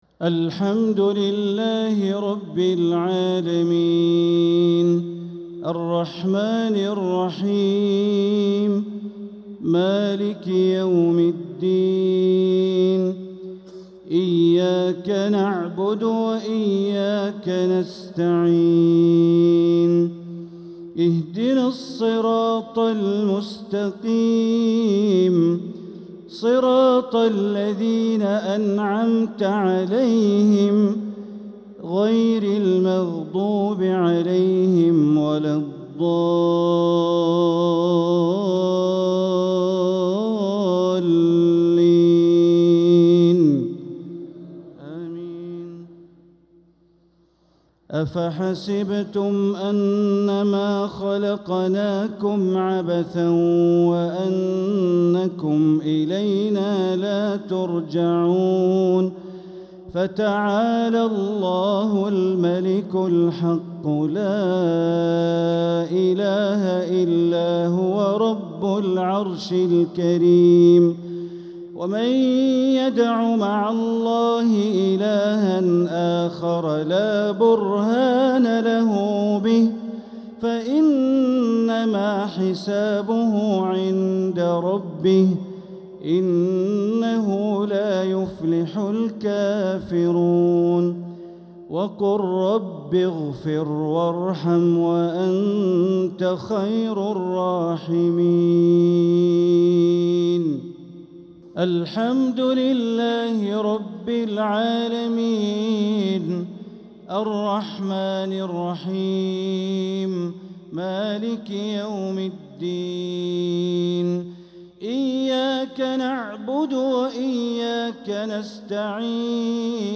عشاء الإثنين 3-9-1446هـ خواتيم سورتي المؤمنون (115-118) و القيامة (36-40) | Isha prayer from Surat al-Mu'minun & al-Qiyamah 3-3-2025 > 1446 🕋 > الفروض - تلاوات الحرمين